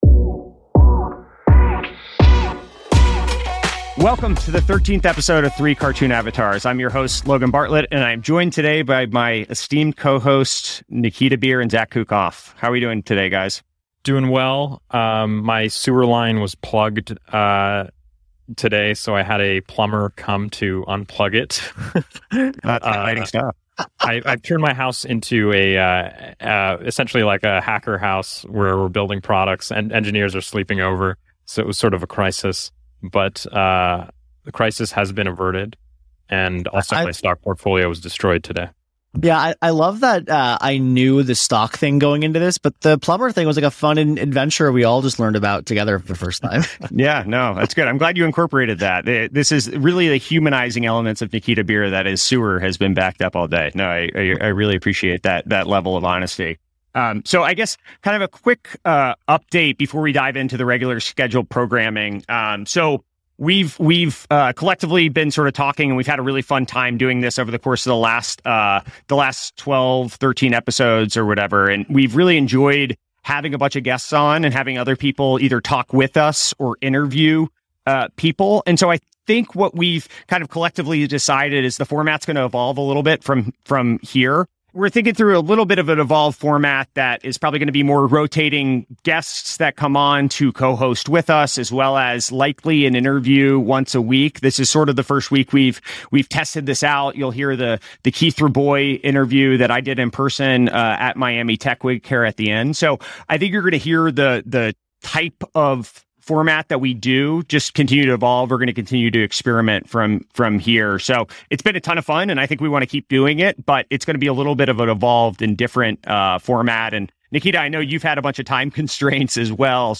EP 13: An Interview with Keith Rabois on Why He Tweets and Miami Tech Week, Netflix Loses 200K Subscribers, and Even More Elon